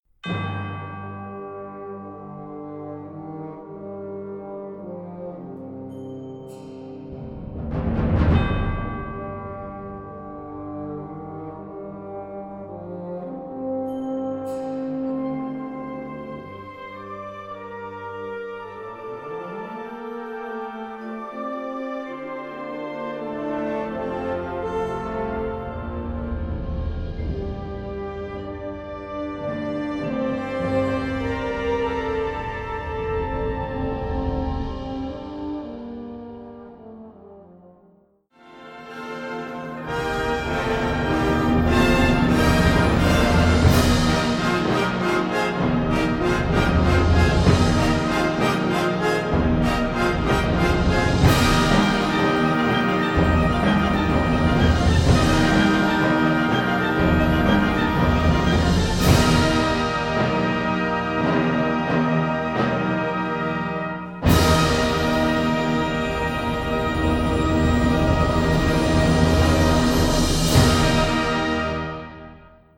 Categorie Harmonie/Fanfare/Brass-orkest
Subcategorie Hedendaagse muziek (1945-heden)
Bezetting Ha (harmonieorkest)